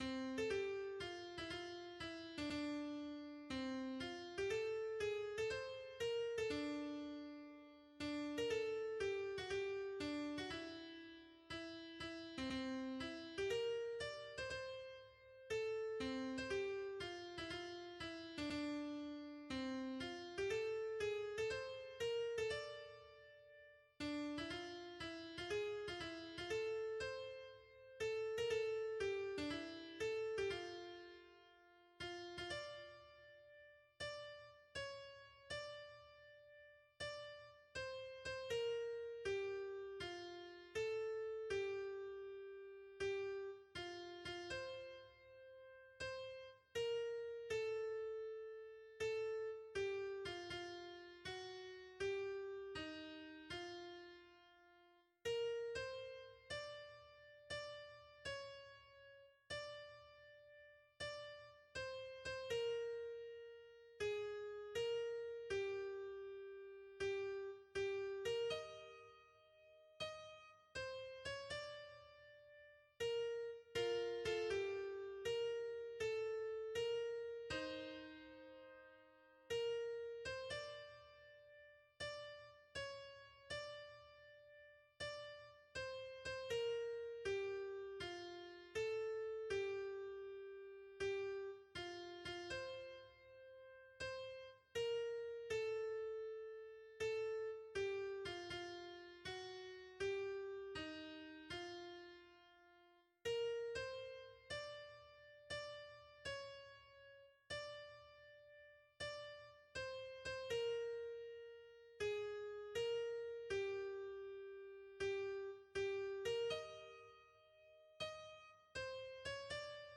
Aqui se encontra uma partitura mais simples, contendo apenas a linha melódica, que foi transcrita para meio eletrônico (.mp3, .mid, .pdf). Dessa transcrição, foi gravada, com auxílio de computador, essa linha melódica (deve-se imaginar o coro a quatro vozes, como sugere o original).
Áudio Karaoke -